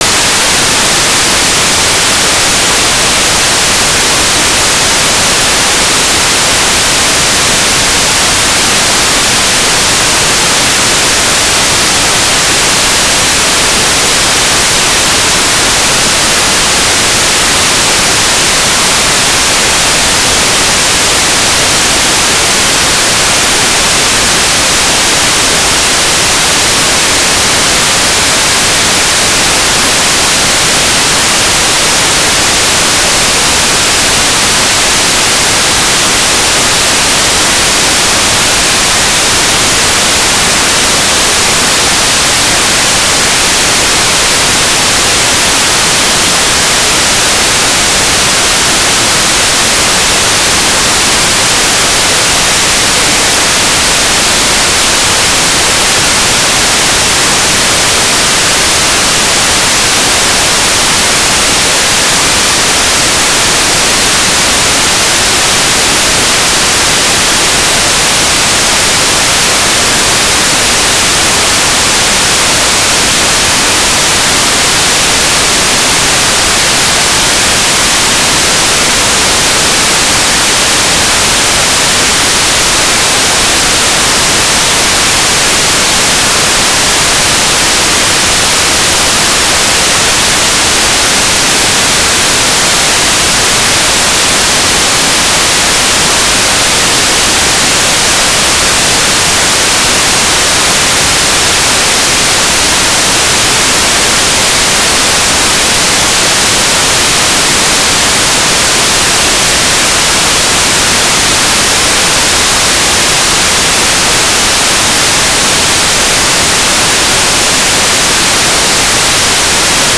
"transmitter_description": "Mode U - AFSK 1143 Beacon TLM",
"transmitter_mode": "AFSK",